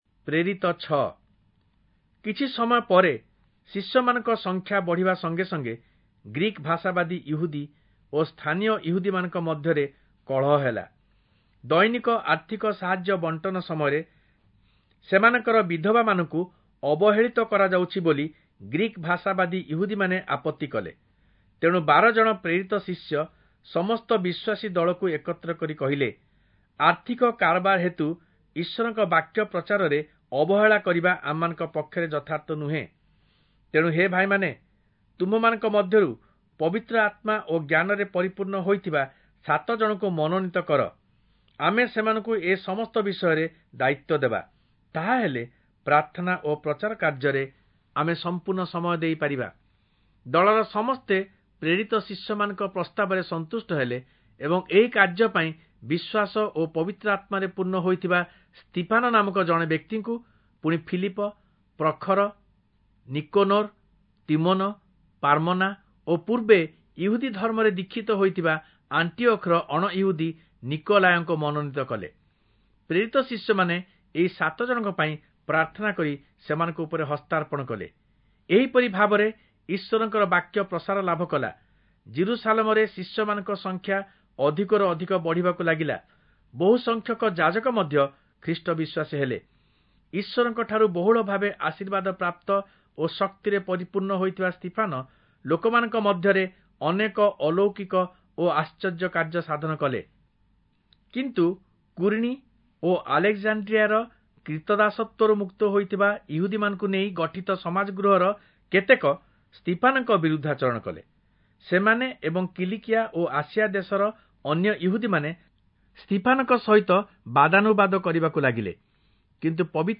Oriya Audio Bible - Acts 8 in Bnv bible version